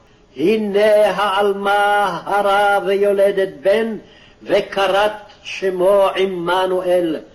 Isaiah 7:14b reading (click):